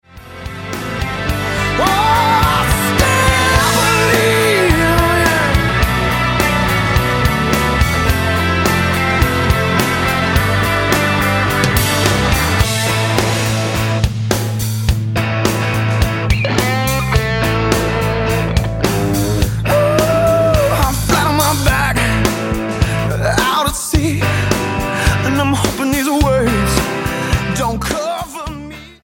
New American rock singer/guitarist
Style: Rock